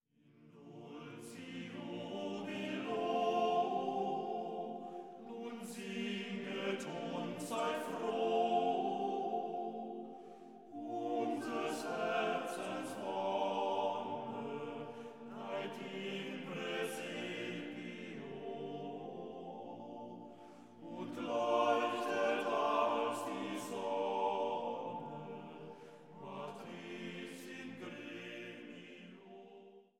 Weihnachtliche Chorsätze und Orgelmusik